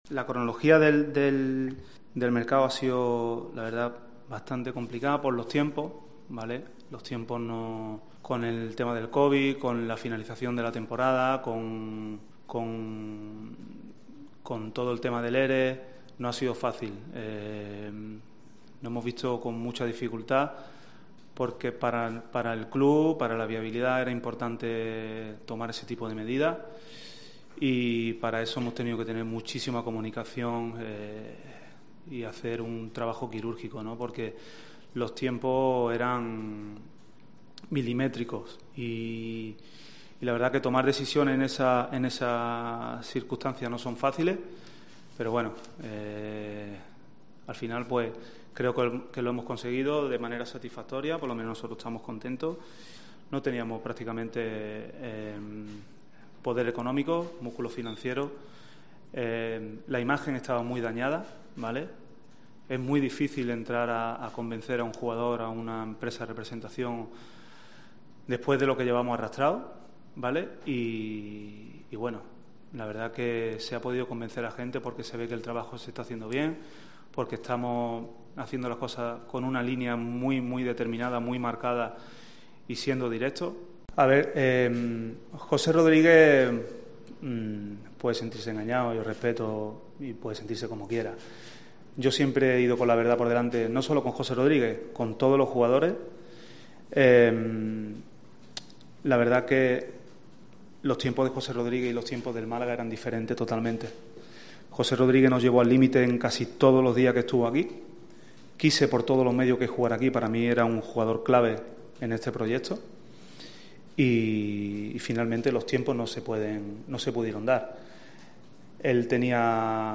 rueda de prensa
en La Rosaleda.